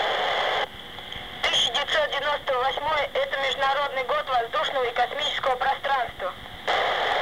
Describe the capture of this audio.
Recorders was done by me on 15/11/98 23:04 utc with FT 203R hand-RTX , antenna Jpole homemade.